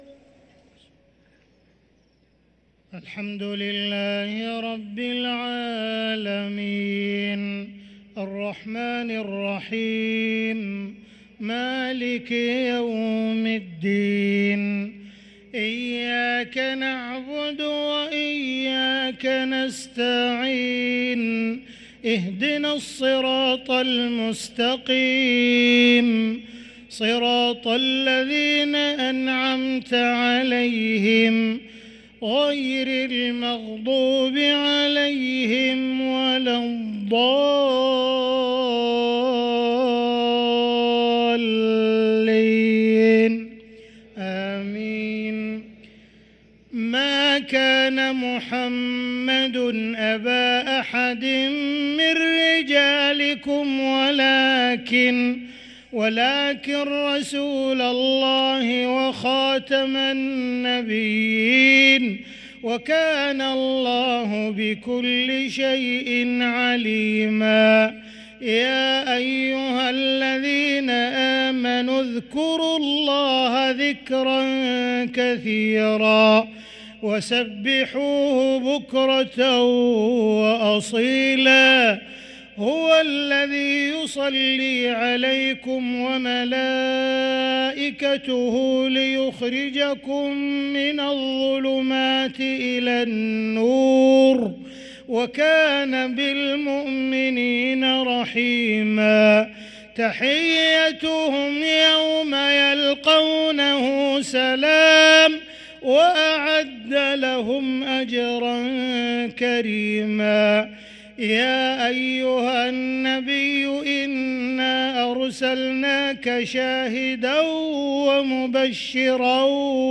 صلاة المغرب للقارئ عبدالرحمن السديس 15 رمضان 1444 هـ
تِلَاوَات الْحَرَمَيْن .